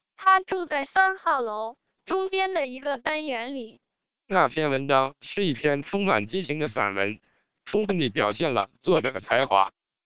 Experts haven't found significant difference in sounding between SPR 1200 and MELPe 1200 vocoders.
You can play and listen short samples of the source speech as well as the speech processed by these vocoders for any of 20 languages, using links in the table below.